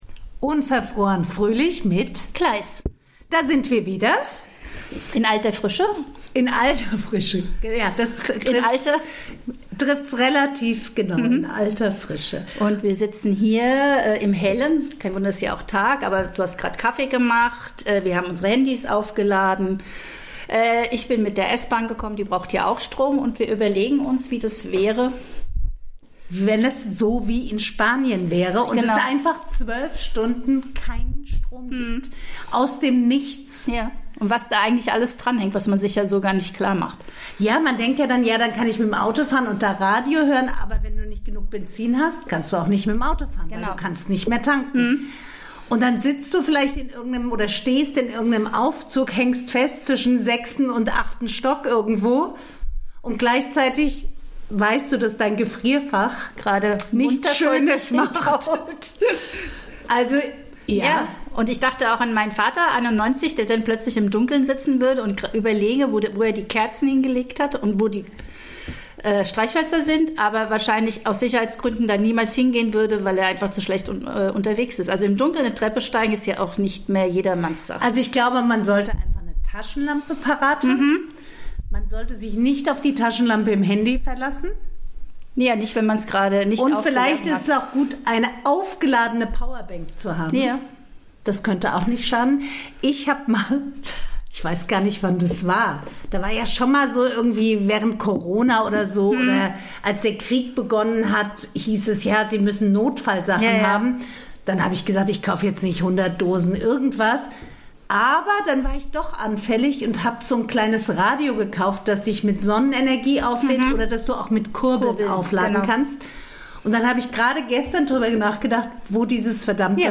reden die beiden Podcasterinnen über Stromversorgung, politische Neubesetzung und den Vorteil des Sitzpinkelns.